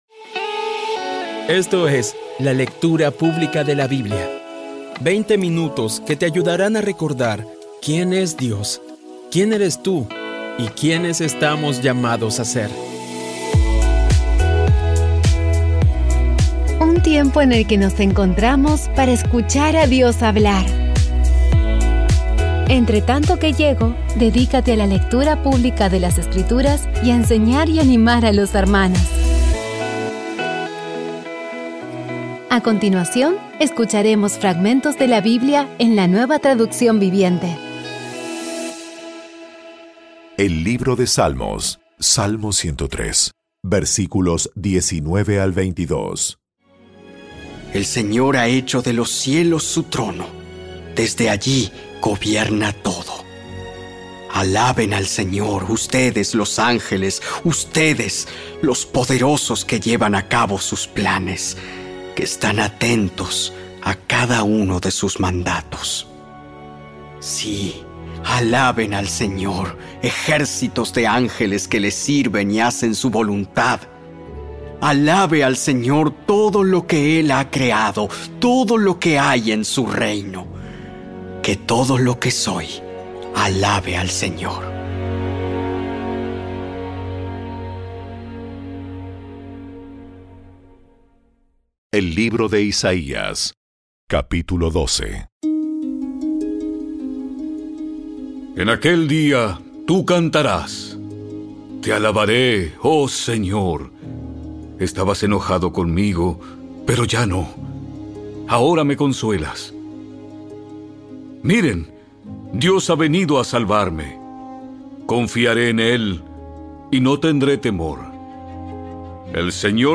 Audio Biblia Dramatizada Episodio 251
Poco a poco y con las maravillosas voces actuadas de los protagonistas vas degustando las palabras de esa guía que Dios nos dio.